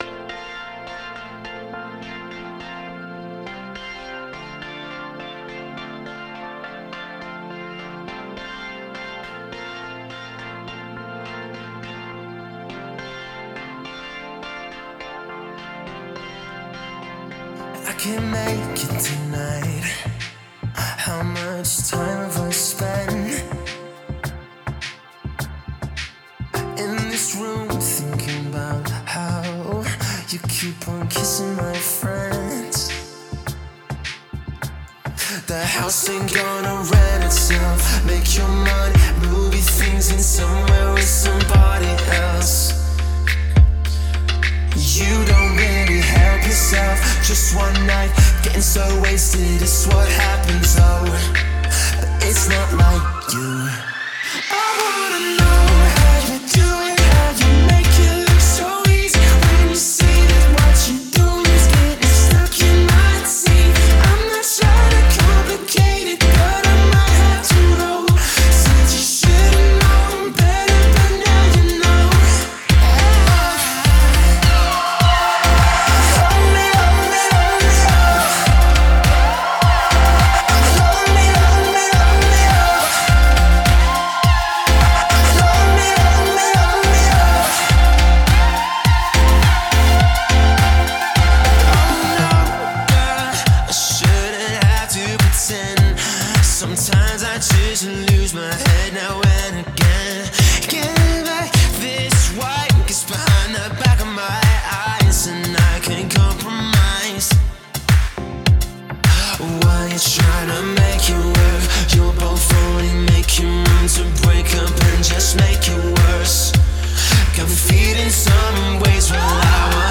Pop/Rock
modern pop-indie/rock music.